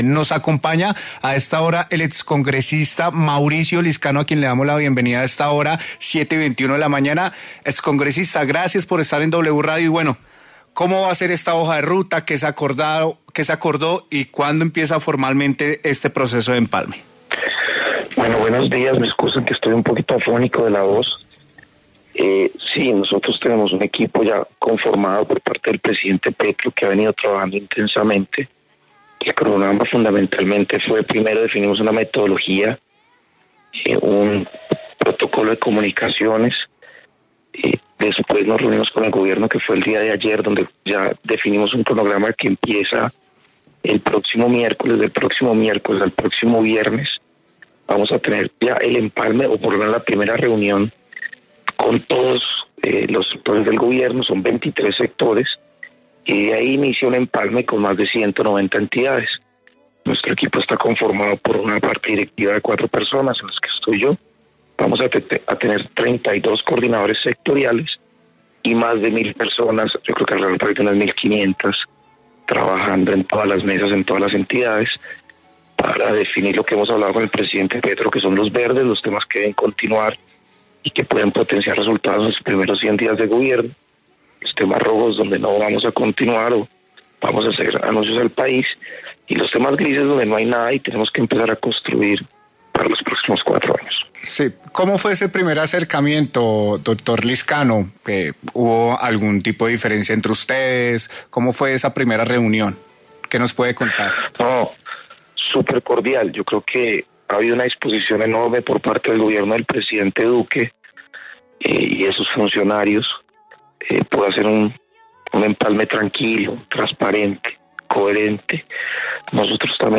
Mauricio Lizcano, miembro del equipo de empalme de Gustavo Petro habló en W Fin de Semana sobre las reuniones de empalme que iniciarán entre el gobierno entrante y saliente.